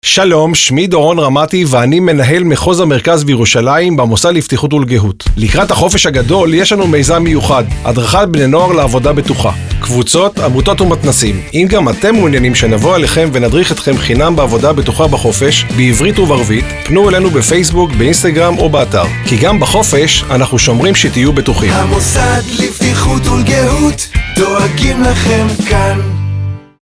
עבודת נוער בקיץ - במסגרת הקמפיין שלנו לעבודת נוער בקיץ לצד הכינוס לנוער שערכנו יצאנו בקמפיין דיגיטל באתרי אינטרנט וברשתות החברתיות, קמפיין רדיו וסדרת כתבות ייעודיות ב-ynet.